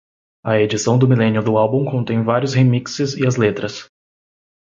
Pronúnciase como (IPA)
/e.d͡ʒiˈsɐ̃w̃/